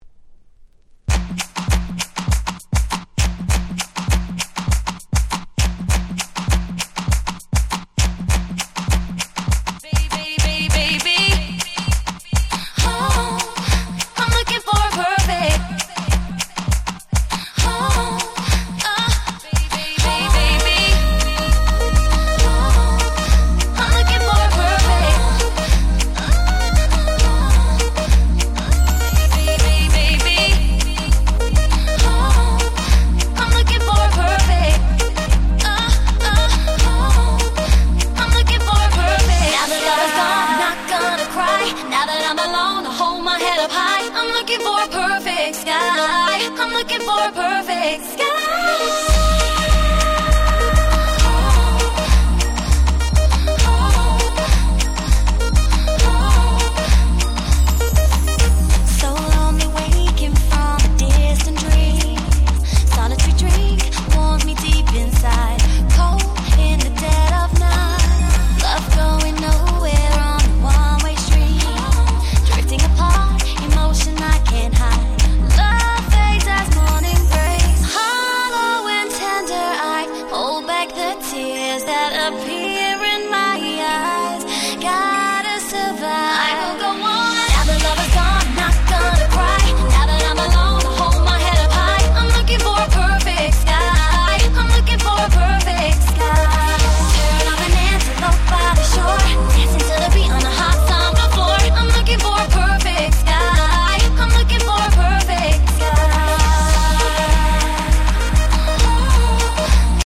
08' Nice Regga R&B !!